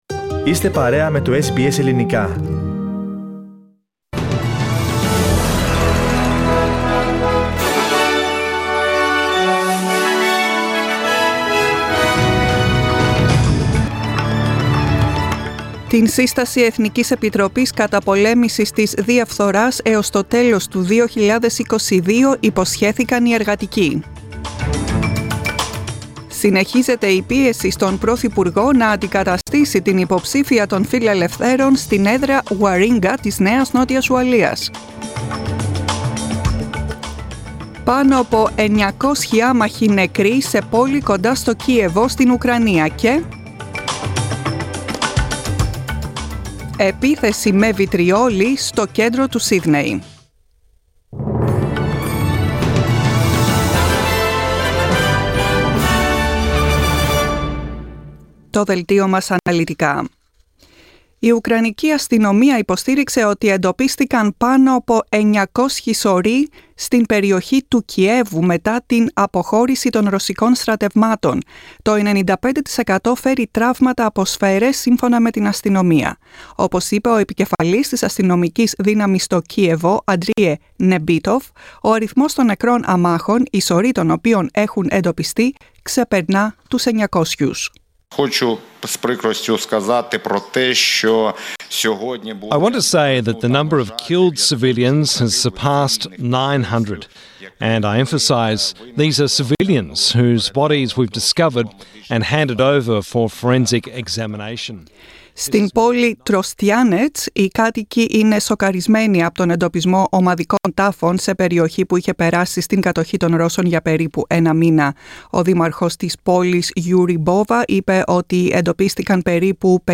Δελτίο ειδήσεων στα ελληνικά, 16.04.22